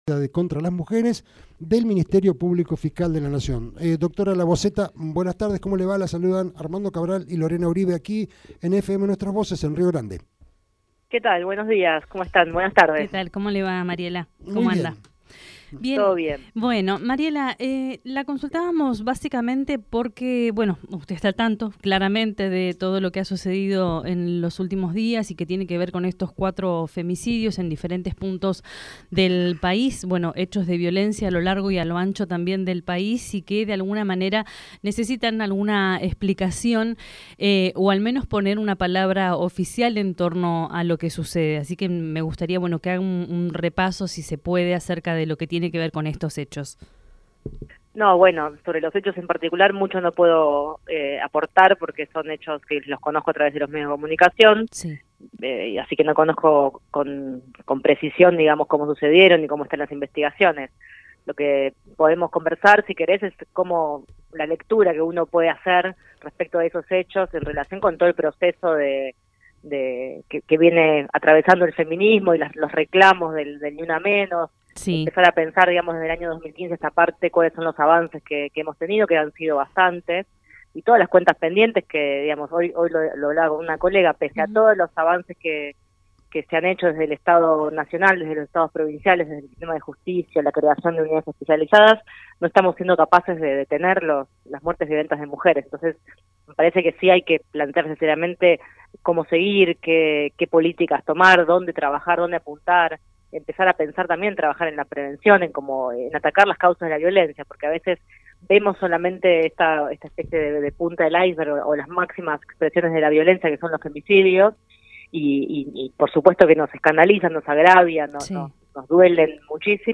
Mierc 18/09/19.- La Dra. Miriam Labozzeta es la titular de la Unidad Fiscal Especializada en violencia contra las mujeres (UFEM) del Ministerio Publico Fiscal de la Mujer y en dialogo con Reporte Diario y en una extensa entrevista se explayó sobre los casos de violencia contra las mujeres que se vienen dando en todo el país, los avances en el sistema de justicia y porque no se ha podido lograr detener estos femicidios, pero no se puede llegar al porqué de las causas.